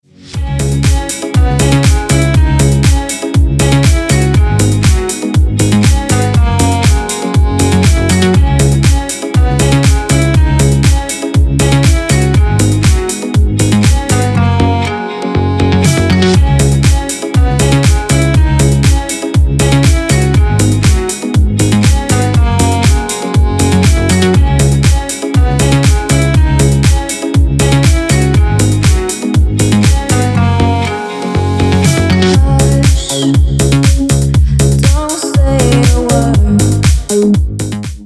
Dance релизы на входящий
• Песня: Рингтон, нарезка